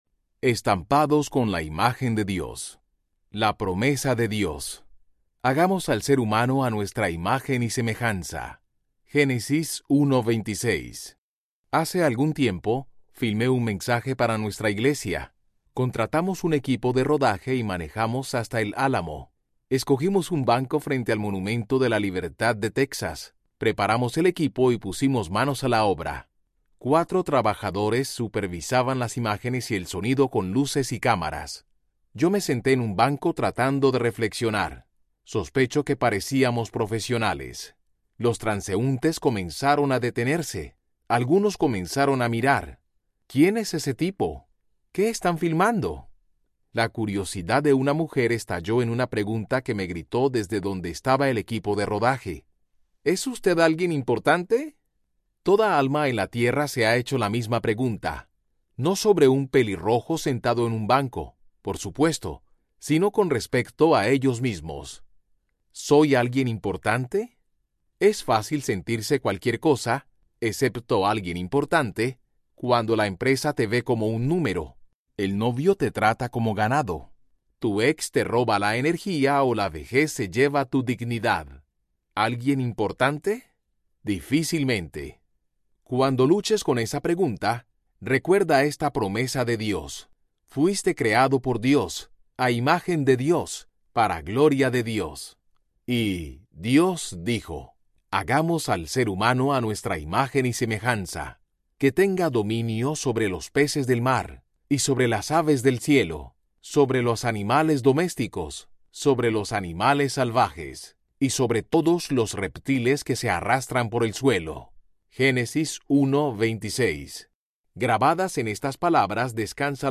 Esperanza inconmovible Audiobook